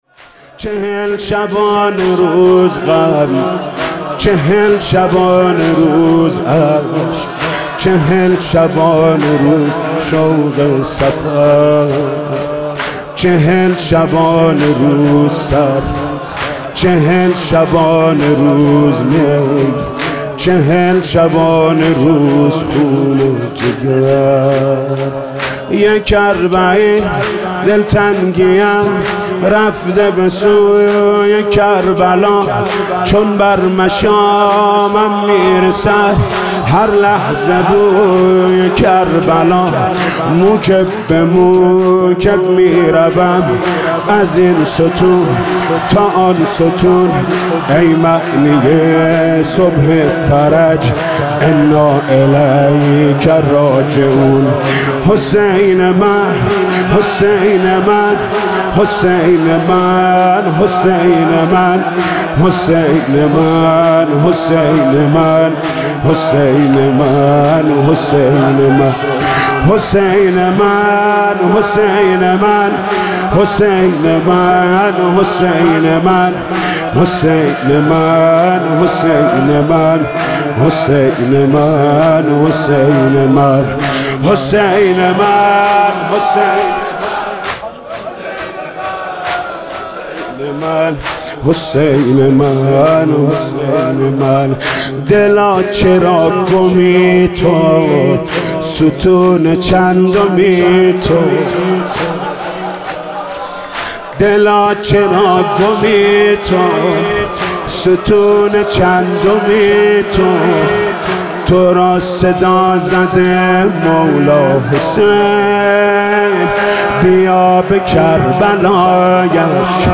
دانلود مداحی ها و نوحه های ویژه پیاده روی اربعین